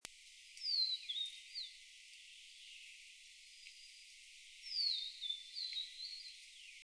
59-2玉山2012黃腹琉璃3.mp3
物種名稱 黃腹琉璃 Niltava vivida vivida
錄音地點 南投縣 信義鄉 玉山塔塔加
21 錄音環境 森林 發聲個體 行為描述 鳴唱 錄音器材 錄音: 廠牌 Denon Portable IC Recorder 型號 DN-F20R 收音: 廠牌 Sennheiser 型號 ME 67 標籤/關鍵字 黃腹琉璃 備註說明 MP3檔案 59-2玉山2012黃腹琉璃3.mp3